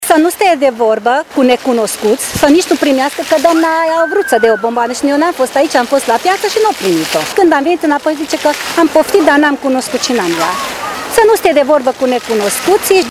Astăzi, în unul din parcurile din Tîrgu-Mureș, adulții s-au dovedit a fi informați și atenți la măsurile de siguranță despre care trebuie le vorbească micuților: